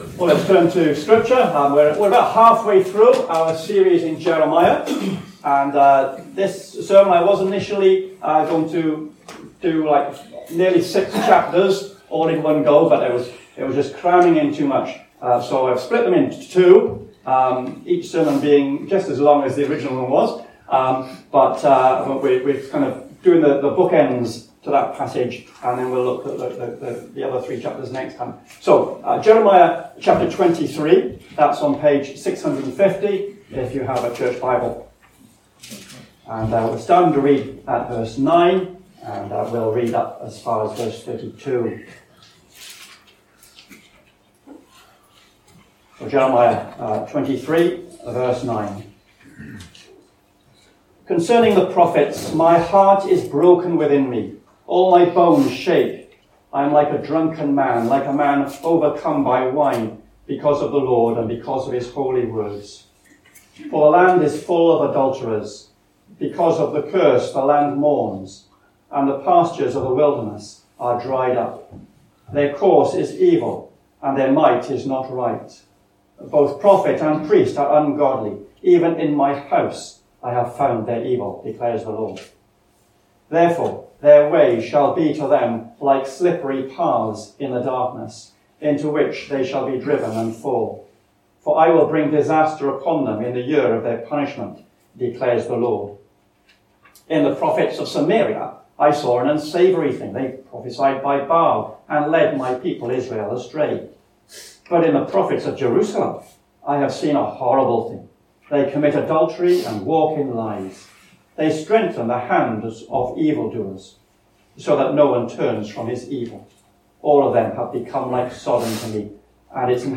A link to the video recording of the 6:00pm service, and an audio recording of the sermon.